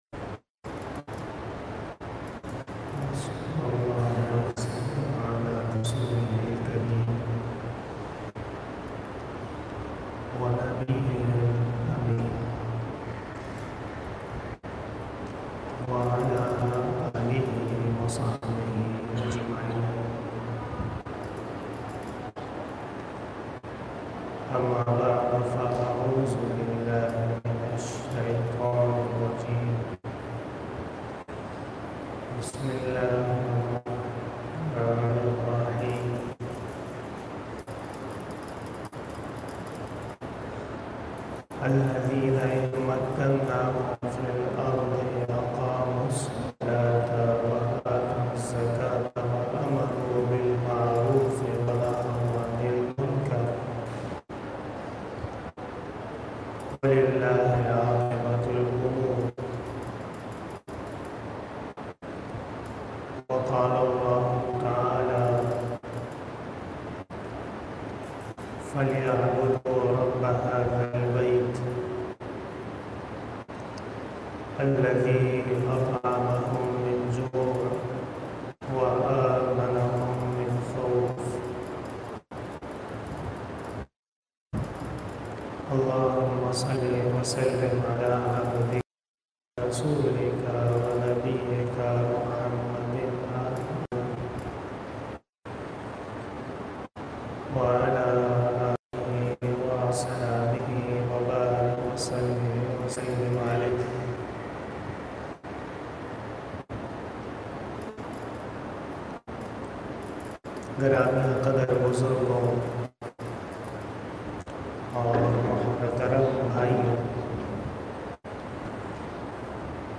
32 BAYAN E JUMMAH 11 Aug 2023 (23 Muharram ul Haraam 1445HJ)
03:46 PM 255 Khitab-e-Jummah 2023 --